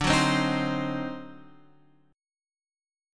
D 7th Flat 9th